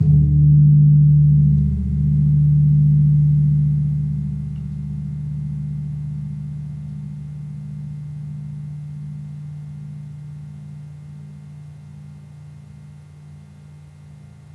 Gong-D1-p.wav